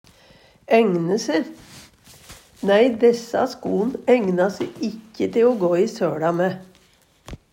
ægne se - Numedalsmål (en-US)